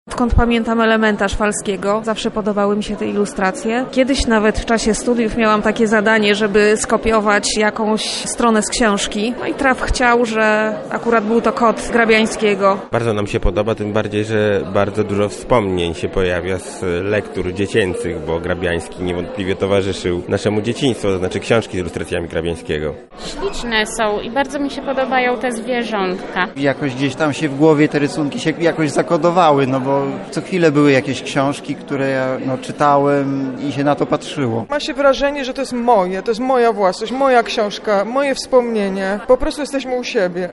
Podczas wernisażu wystawy jego prac zapytaliśmy uczestników co o nich sądzą.